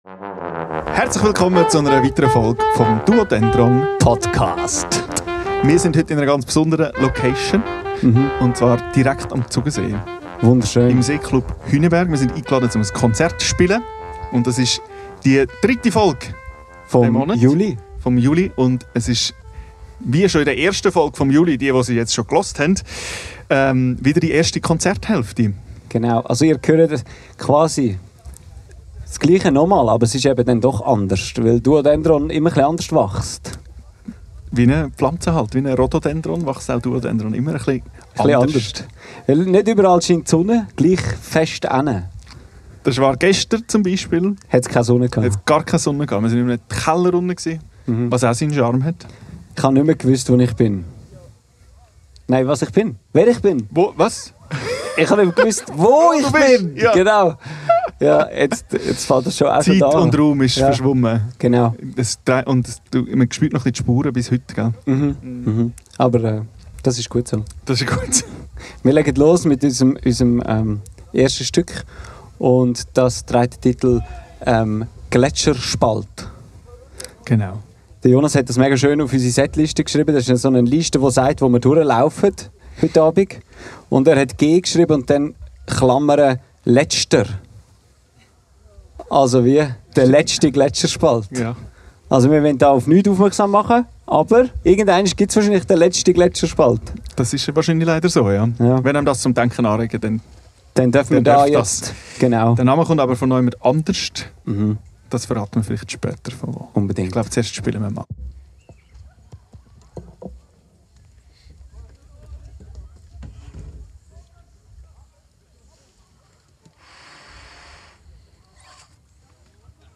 Aufgenommen am 27.06.25 im Seeclub Hünenberg.